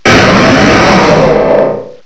sovereignx/sound/direct_sound_samples/cries/aggron_mega.aif at master